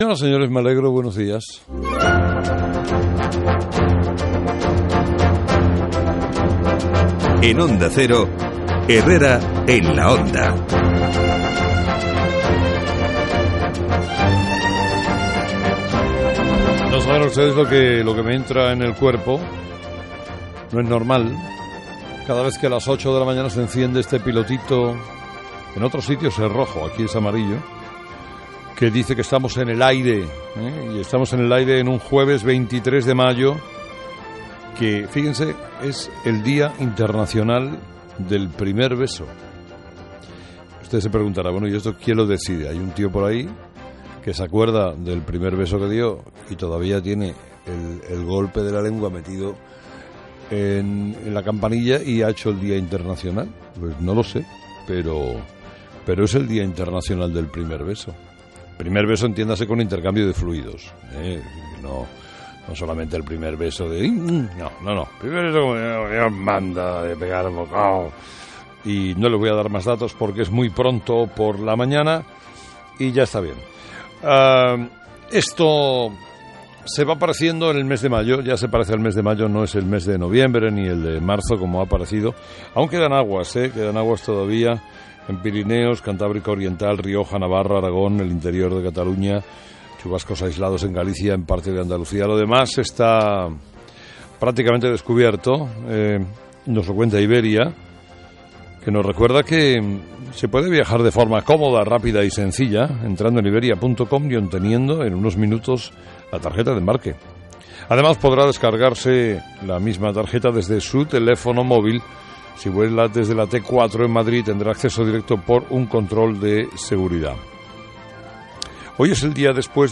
23/05/2013 Editorial de Carlos Herrera: ‘Si Aznar estuvo en estado puro, Rajoy respondió en su estado puro’